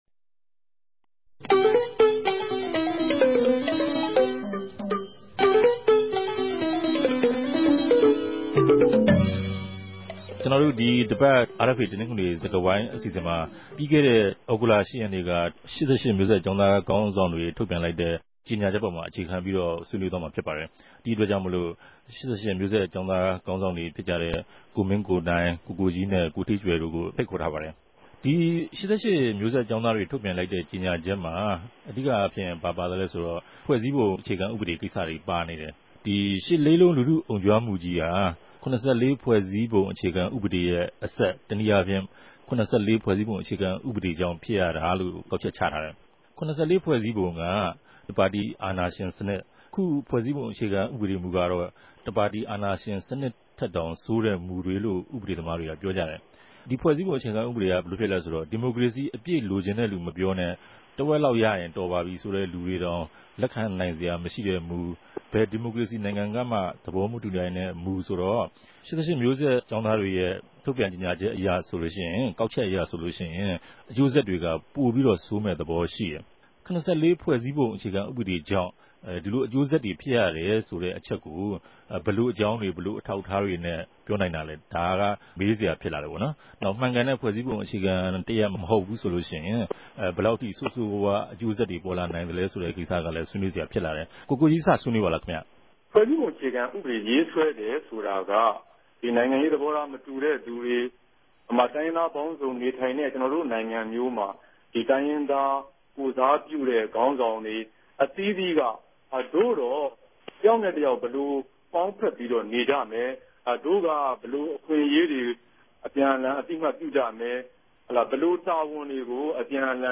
တနဂဿေိံစြကားဝိုင်း
ဝၝရြင်တန် ႟ုံးခဵြပ် စတူဒီယိုထဲမြ